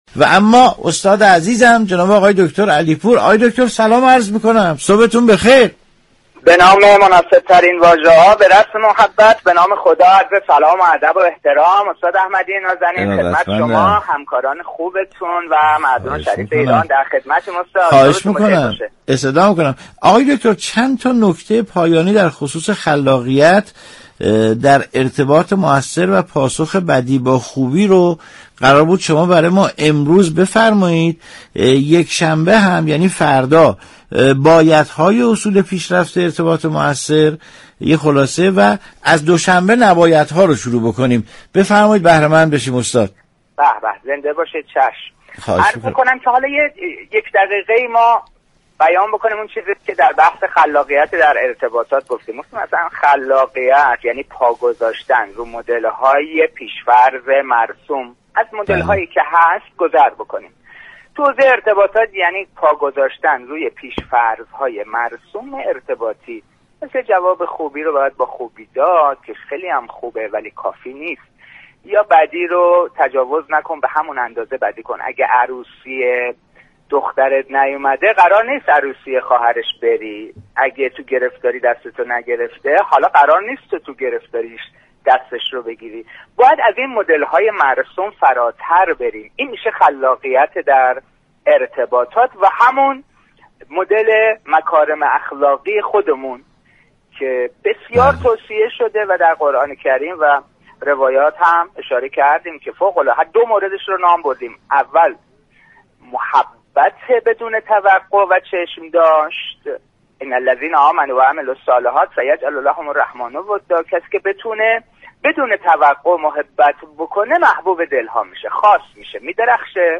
مجله صبحگاهی "تسنیم" با رویكرد اطلاع رسانی همراه با بخش هایی متنوع، شنبه تا پنجشنبه از شبكه ی رادیویی قرآن به صورت زنده تقدیم شنوندگان می شود.